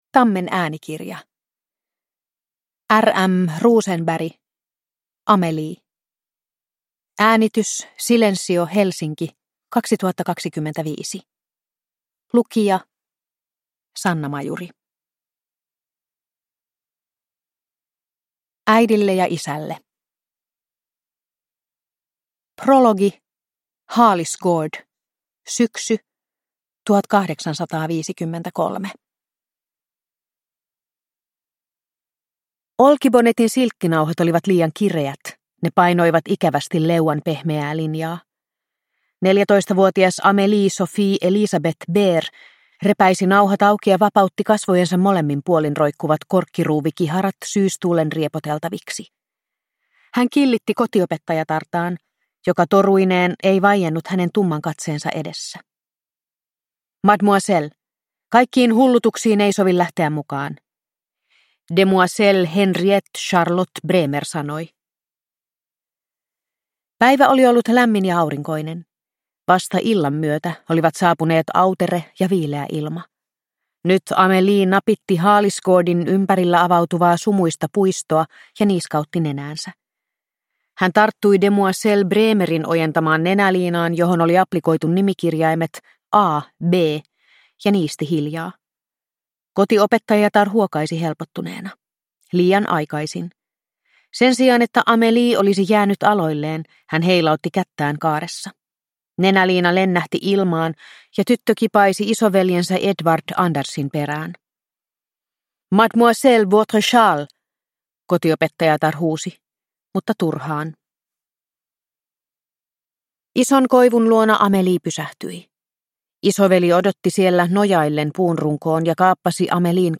Amelie (ljudbok) av R. M. Rosenberg